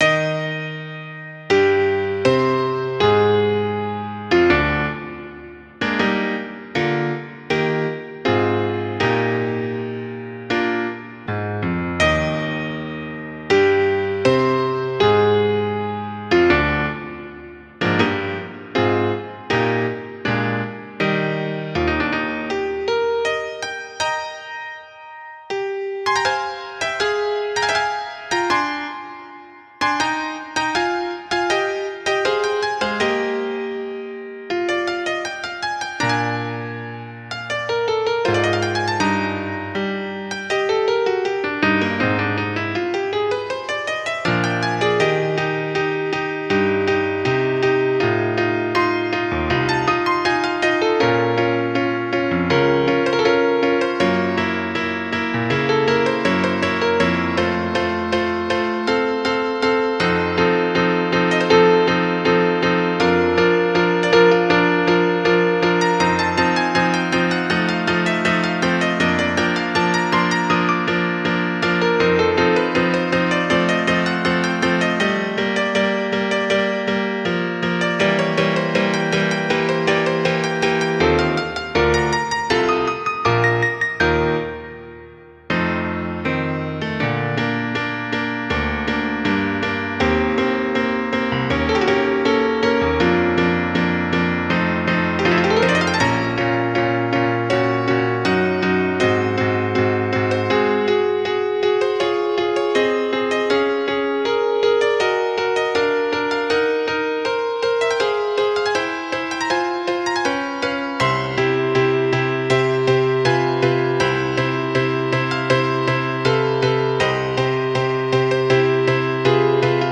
música clásica
sonata